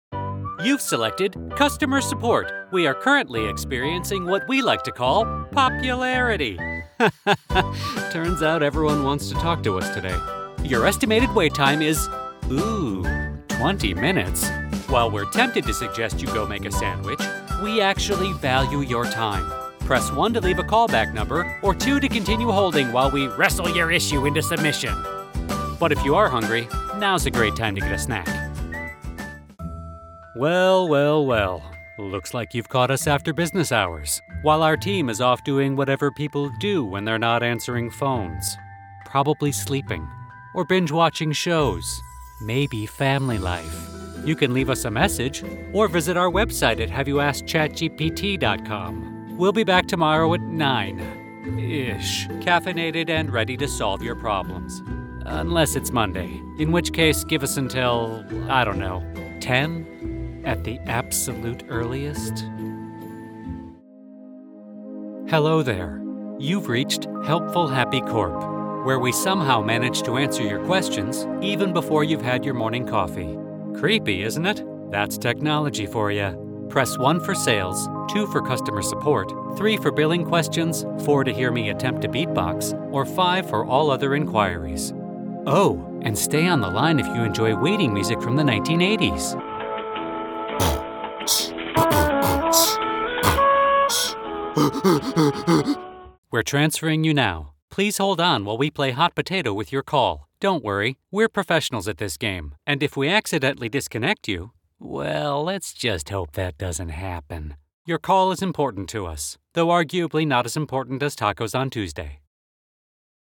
Englisch (Amerikanisch)
Kommerziell, Natürlich, Freundlich, Unverwechselbar, Verspielt
Telefonie